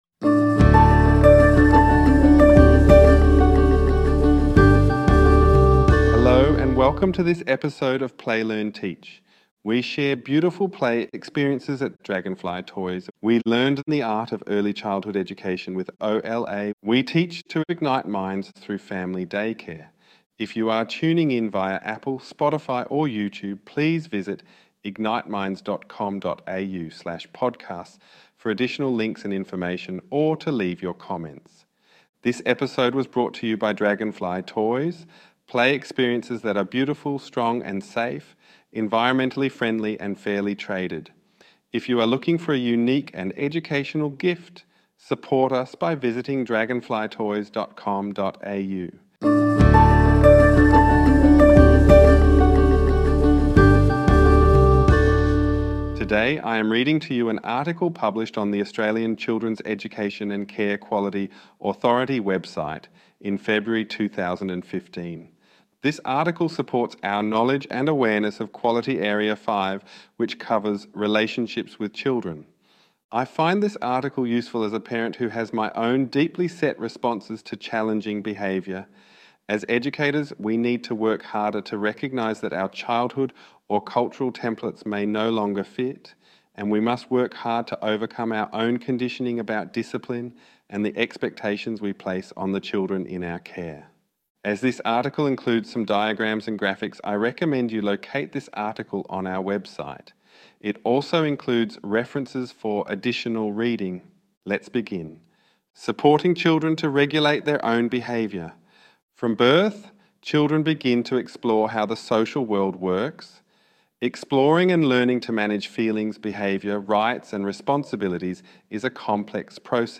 This is a reading of an article published on ACECQA website.